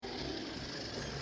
Giant Octopus roar (Hanna Barbera)
Giant_Octopus_Roar_Hanna_Barbera.mp3